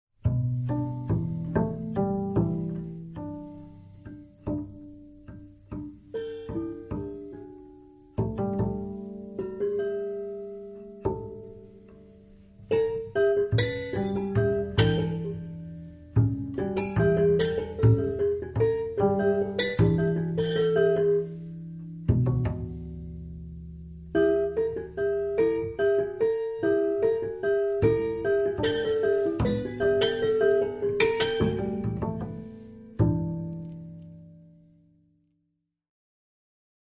at Secret House Studio, Amsterdam
contrabajo
piano preparado